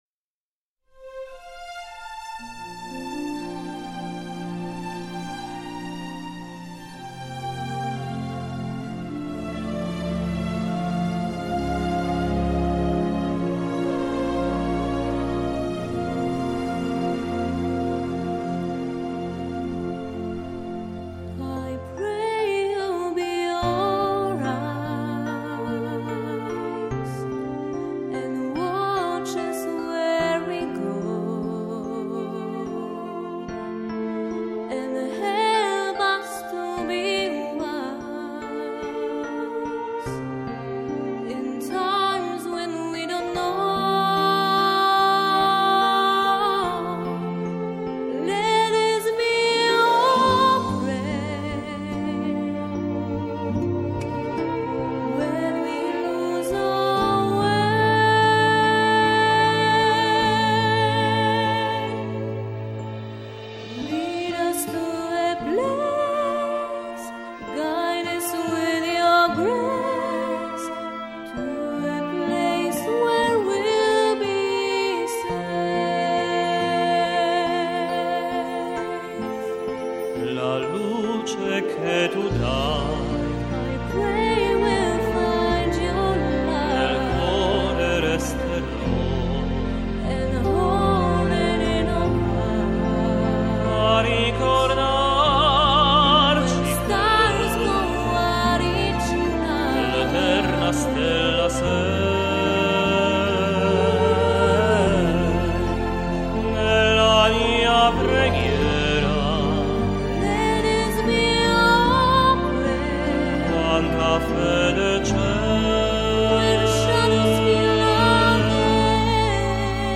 mezzosopran
tenor